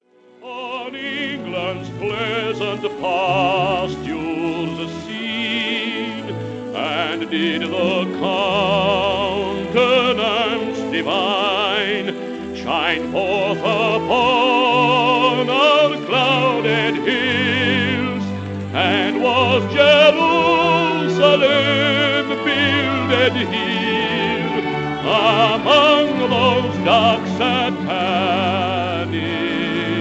the Australian baritone